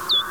Klänge der Fledermäuse
Lasiurus borelis, aufgezeichnet in Arizona, USA.
Alle Rufe auf dieser Seite wurden mit einem Petterson D-980 Fledermaus Detektor aufgezeichnet und mit einem Faktor 10 verlangsamt.
Die Klänge sind mit einer Auflösung von 8 bit bei 44,1 kHz auf einer Soundkarte resampled.